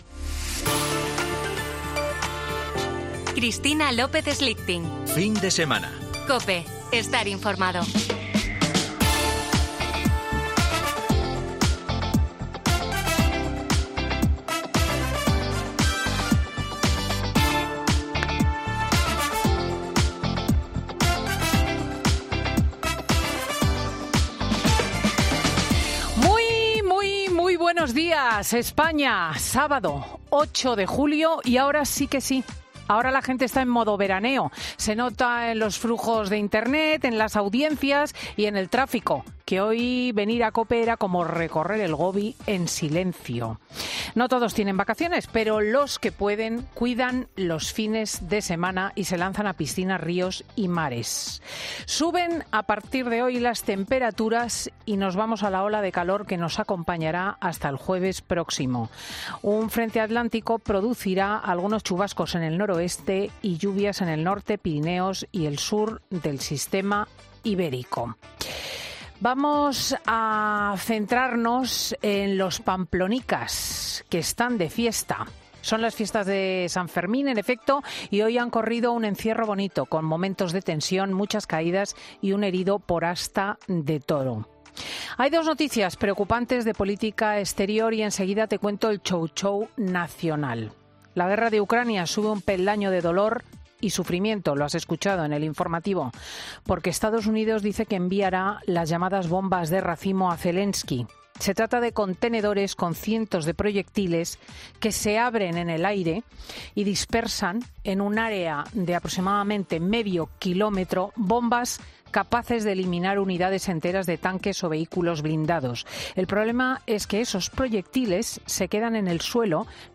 Escucha el monólogo de Cristina López Schlichting de este sábado 8 de julio de 2023